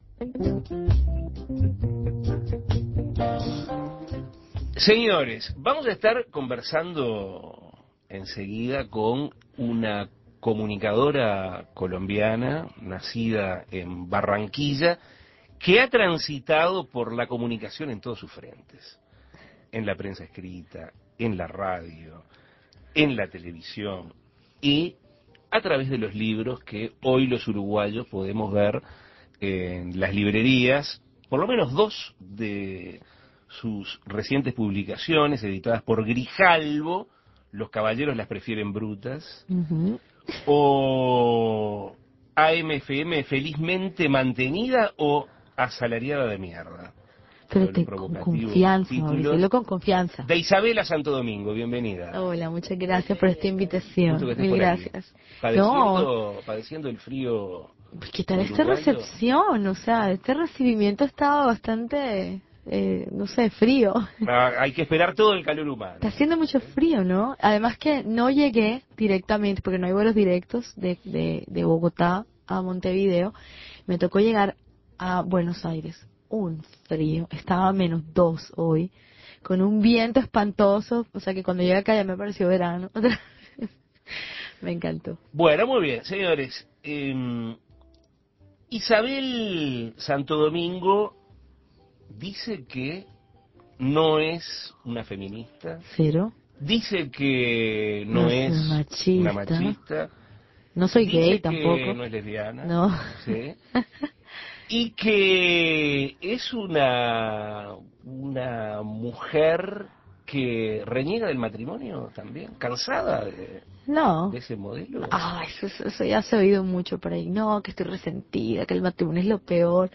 Escuche la entrevista a Isabella Santo Domingo